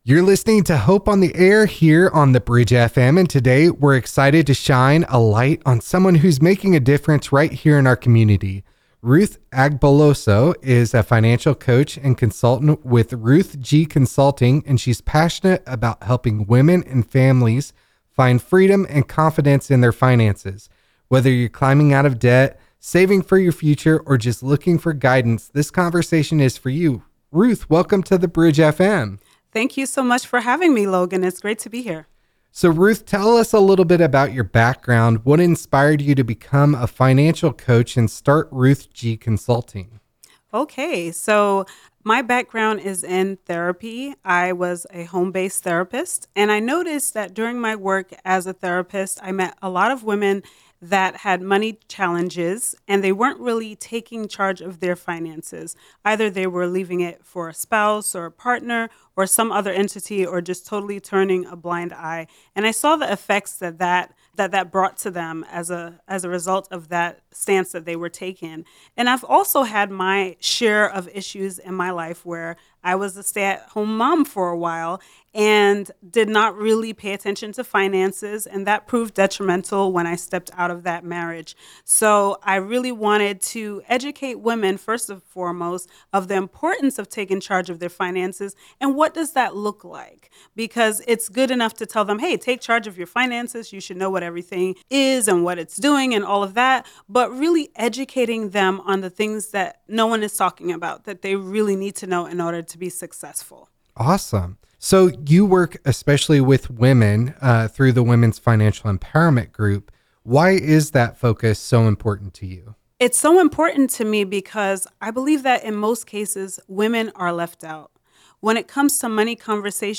Financial Empowerment Interview